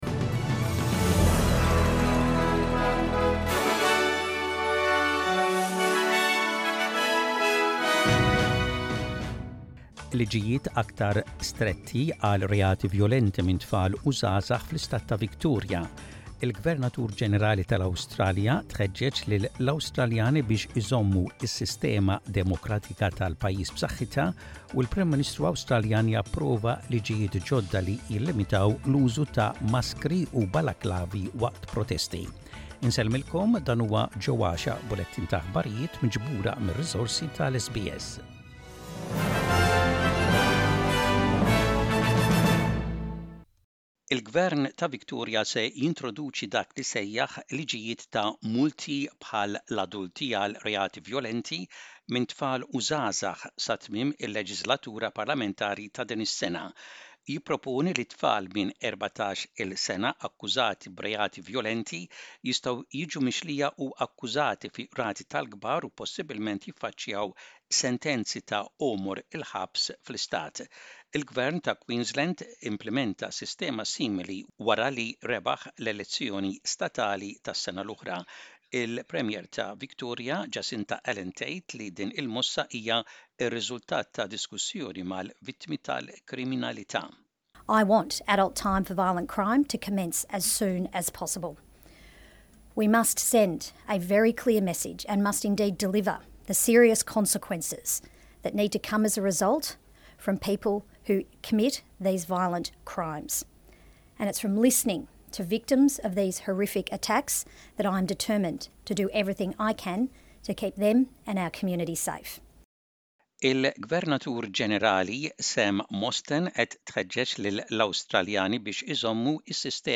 SBS Maltese News: Photo-SBS Maltese